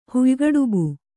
♪ huygaḍubu